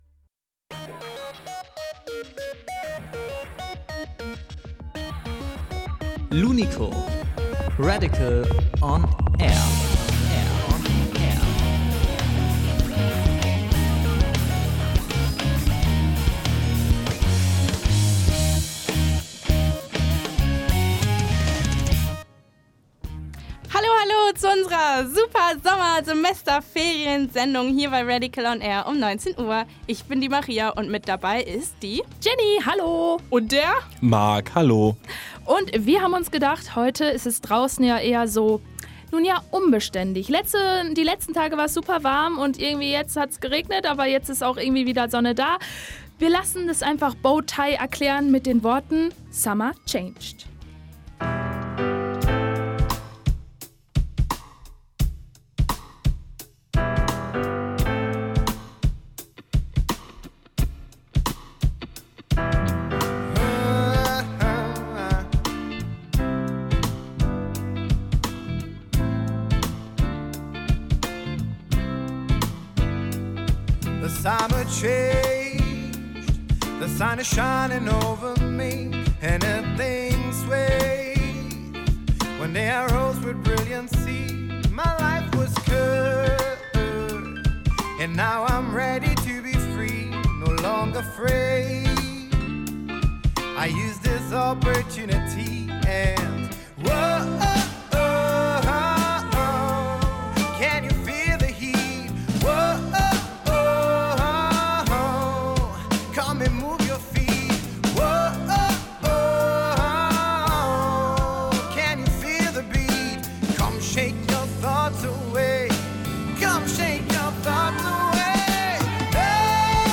live im Interview SMASH BROTHERS